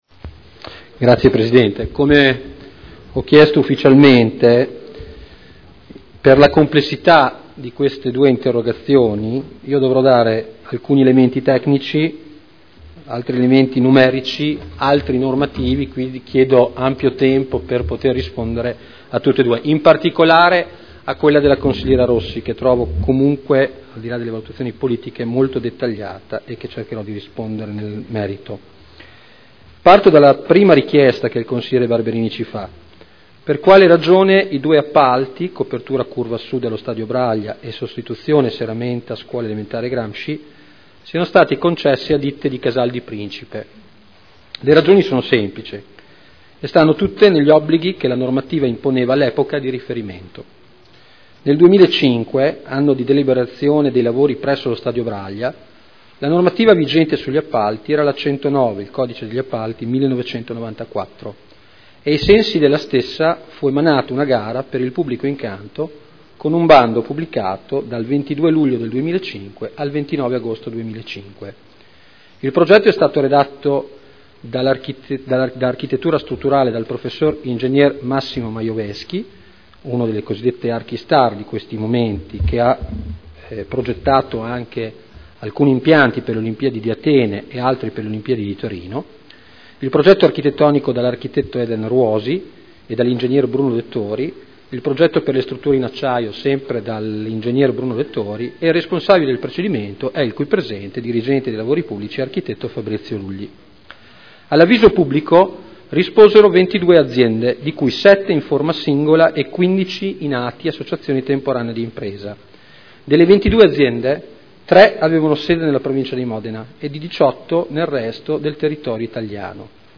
Antonino Marino — Sito Audio Consiglio Comunale